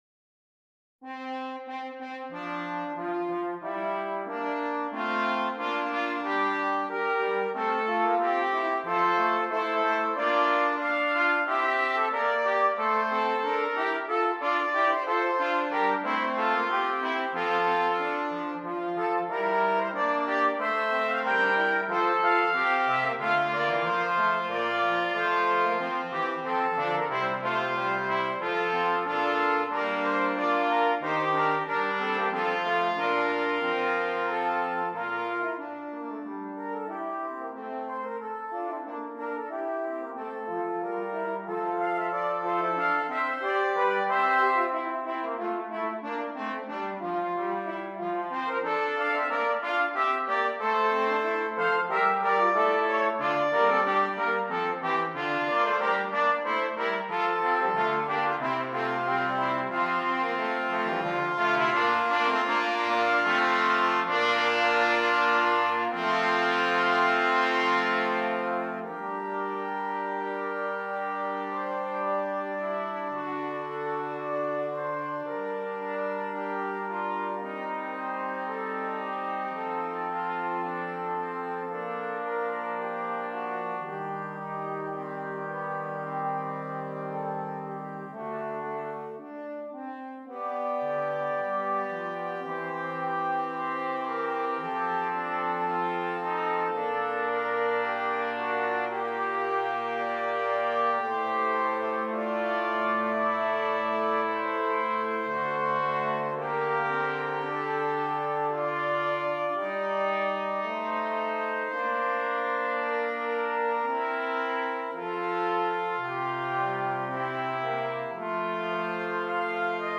Brass Quartet
This trio of short Baroque transcriptions